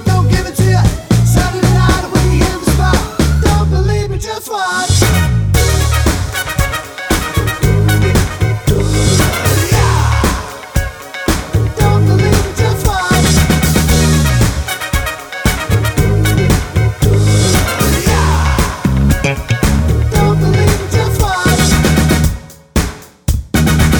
no Backing Vocals Pop (2010s) 2:18 Buy £1.50